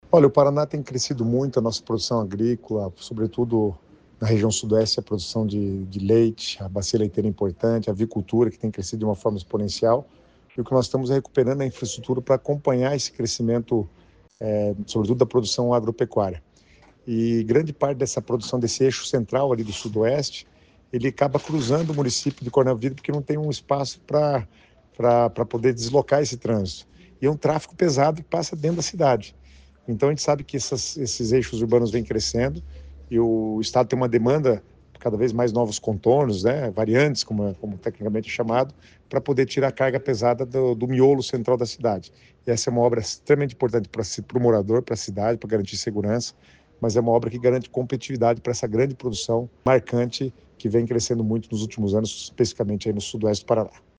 Sonora do secretário das Cidades, Guto Silva, sobre o novo contorno viário de Coronel Vivida | Governo do Estado do Paraná